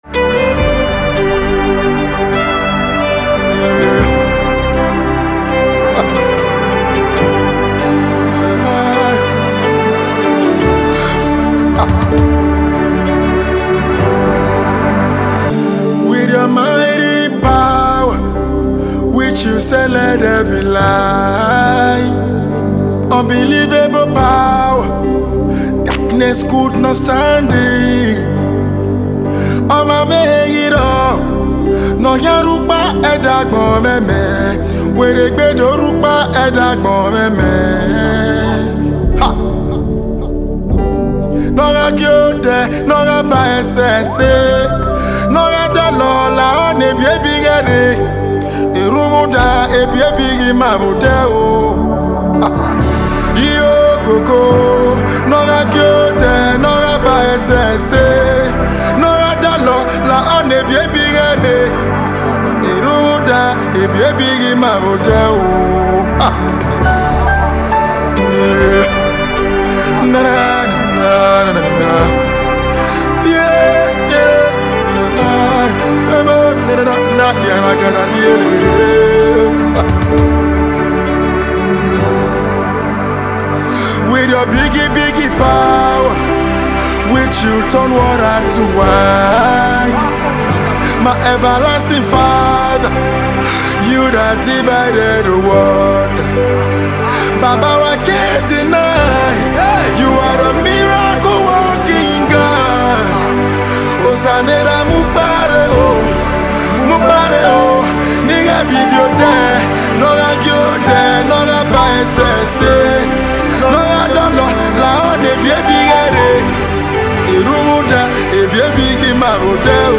Gospel artist